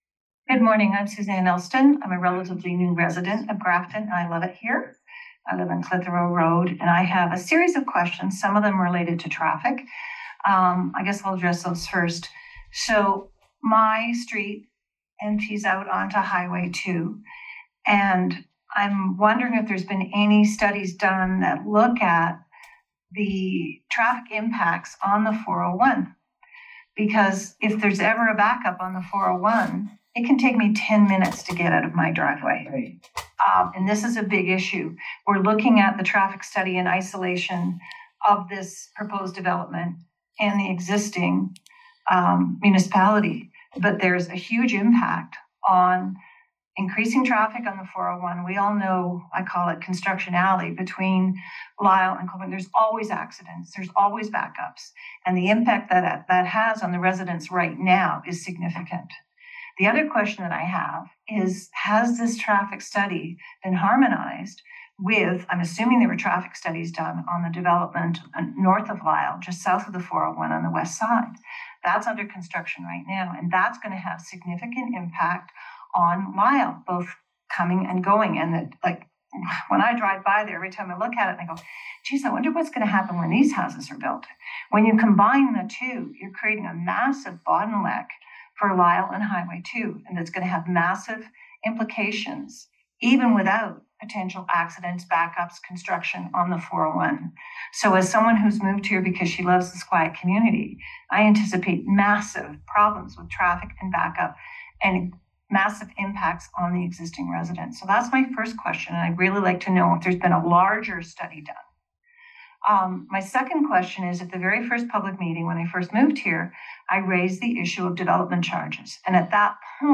Politicians and staff listened to residents who participated in a public meeting about the draft subdivision plan located on Old Danforth Road and Purdy Road.